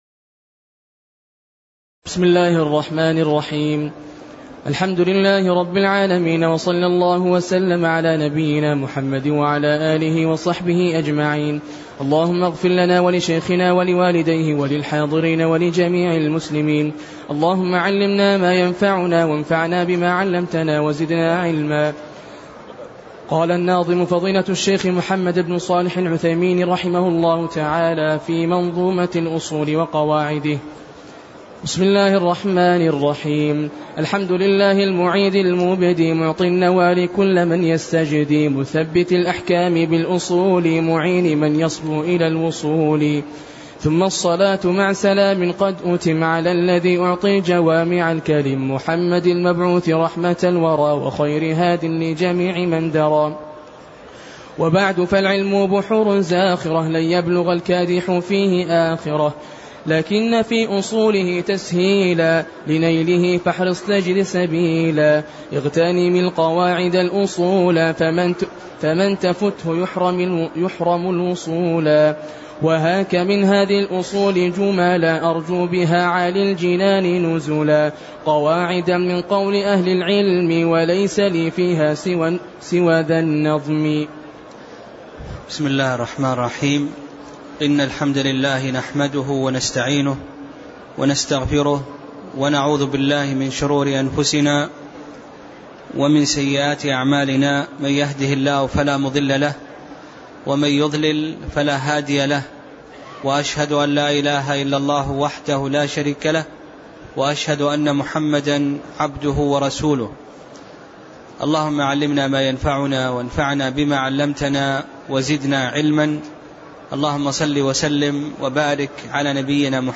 تاريخ النشر ٢٩ رجب ١٤٣٤ هـ المكان: المسجد النبوي الشيخ: فضيلة الشيخ أ.د. خالد بن علي المشيقح فضيلة الشيخ أ.د. خالد بن علي المشيقح المقدمة (01)